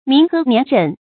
民和年稔 mín hé nián rěn
民和年稔发音
成语注音 ㄇㄧㄣˊ ㄏㄜˊ ㄋㄧㄢˊ ㄖㄣˇ